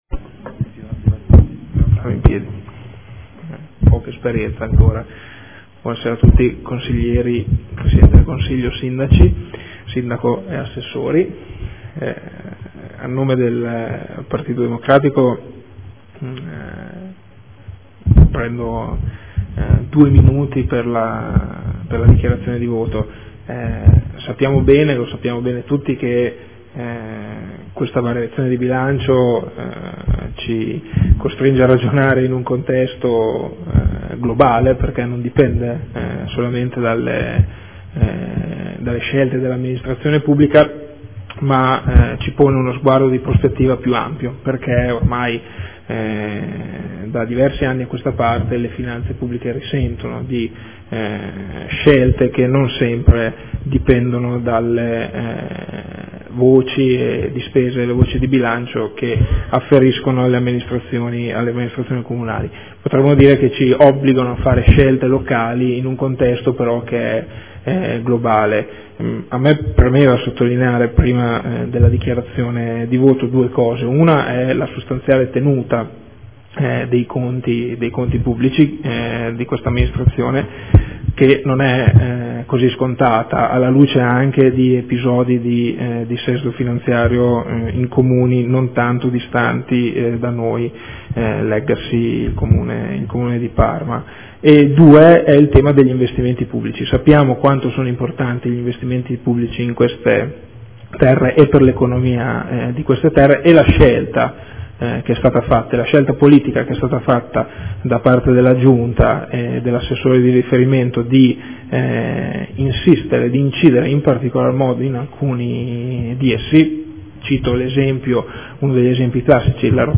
Dichiarazione di voto. Bilancio di Previsione Armonizzato 2014/2016 – Piano Investimenti 2014/2016 – Programma Triennale dei Lavori Pubblici 2014/2016 – Documento Unico di Programmazione 2014/2016 – Variazione di Bilancio n. 2 – Riadozione e conferma degli schemi di Bilancio
Audio Consiglio Comunale